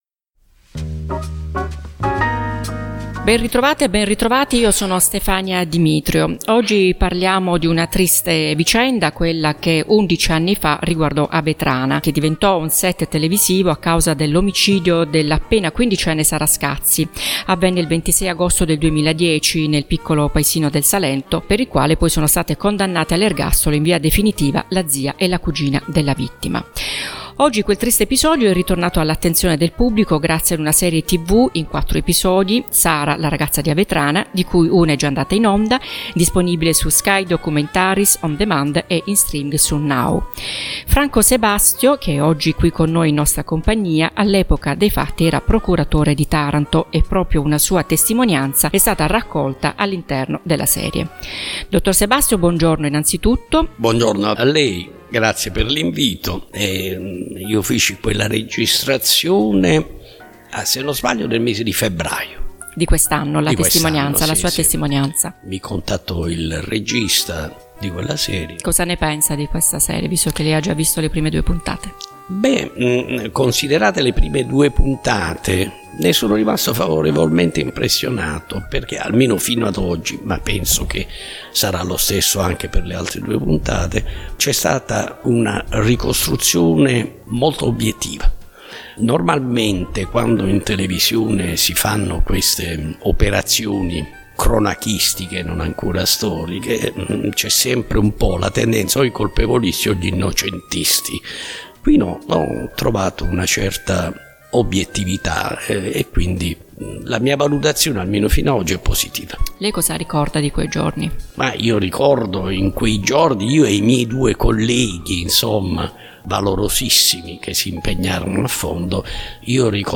Intervista con Franco Sebastio.
Ex-procuratore-Franco-Sebastio.mp3